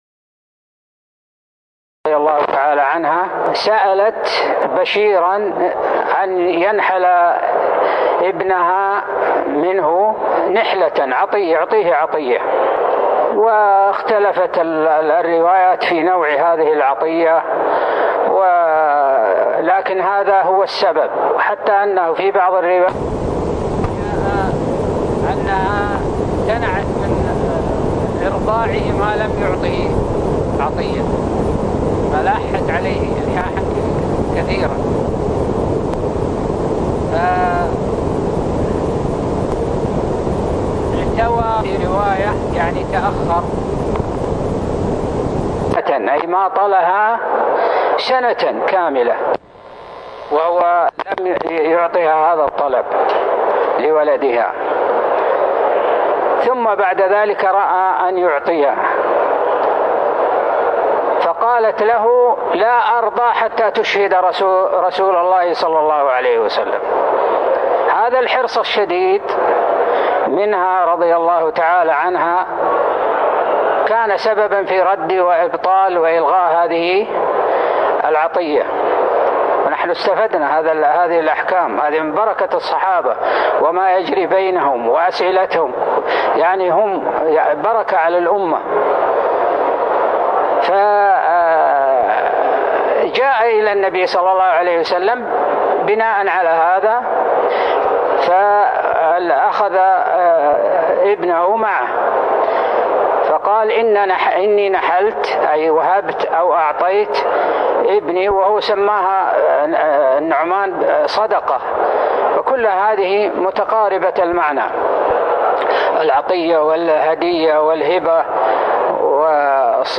تاريخ النشر ٢٠ رجب ١٤٤٠ هـ المكان: المسجد النبوي الشيخ